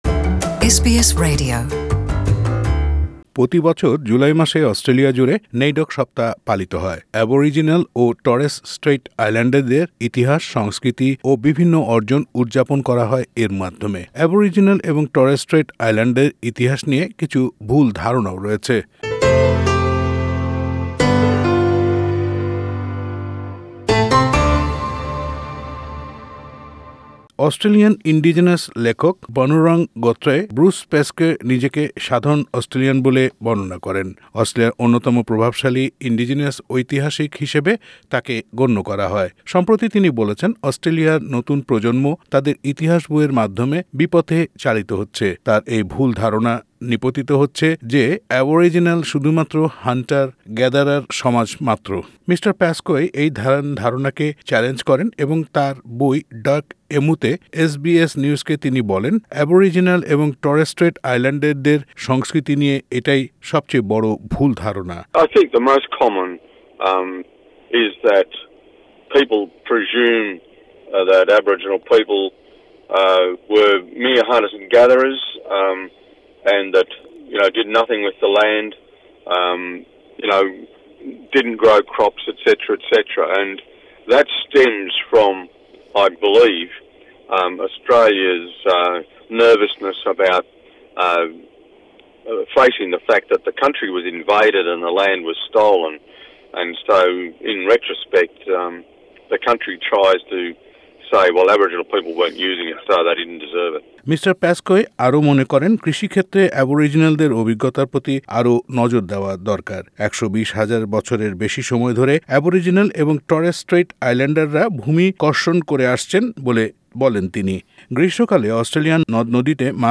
এসবিএস নিউজকে তিনি বলেন, অ্যাবোরিজিনাল এবং টরেস স্ট্রেইট আইল্যান্ডারদের সংস্কৃতি নিয়ে এটাই সবচেয়ে বড় ভুল ধারণা। প্রতিবেদনটি বাংলায় শুনতে উপরের অডিও প্লেয়ারটিতে ক্লিক করুন।